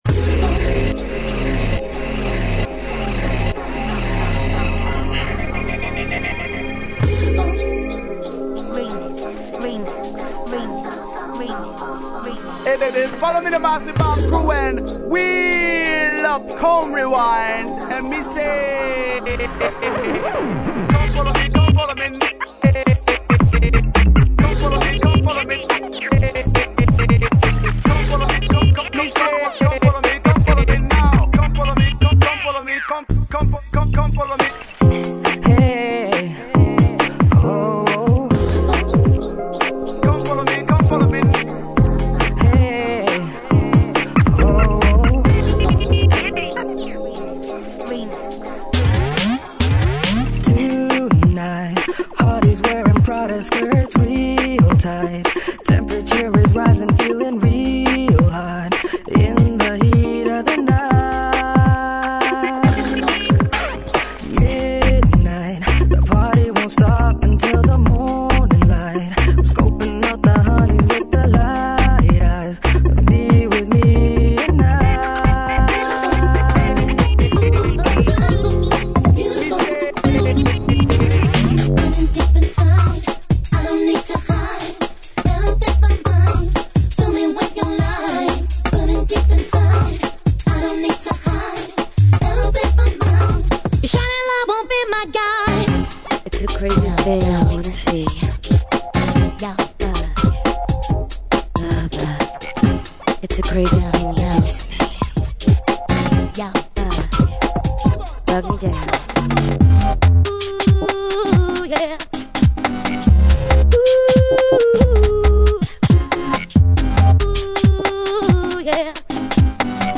The Best 100 Garage Records Mixed onto a 74min cd